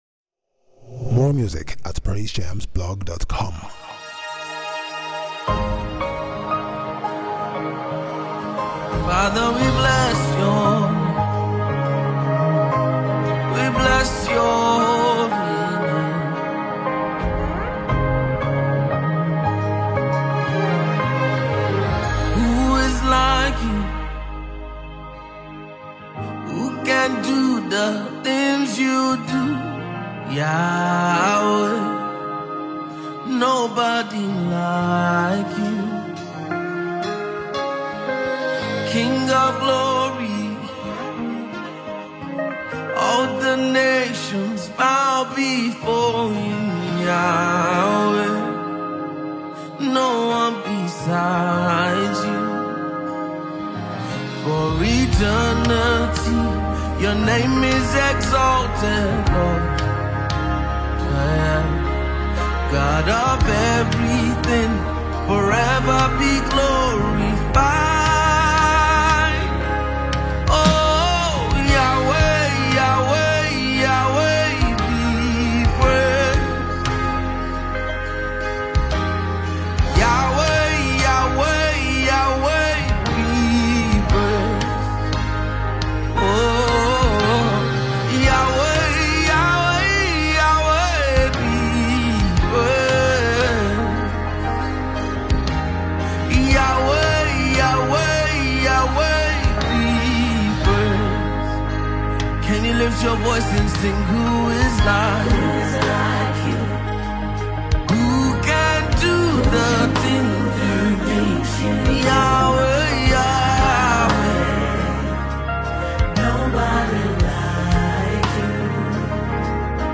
With its heartfelt lyrics and uplifting melodies
worship anthem